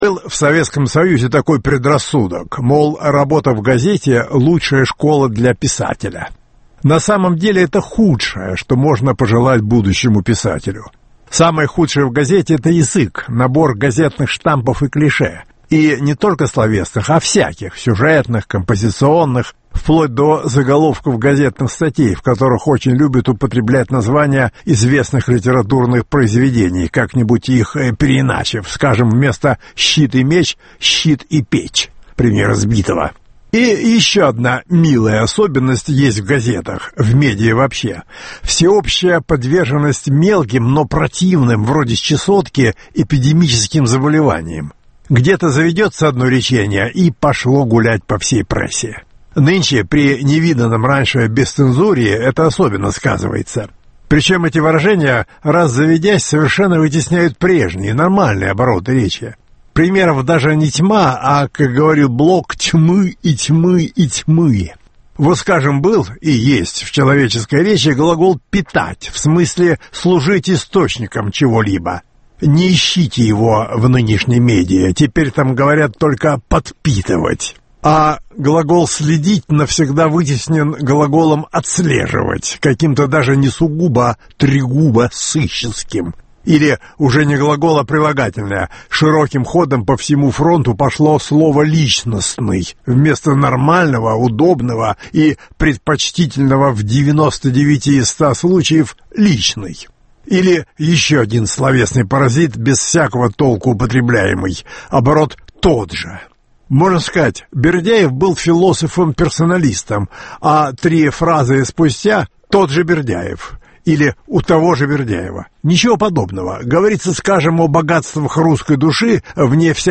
Обустройство закулисья - радиоэссе Бориса Парамонова.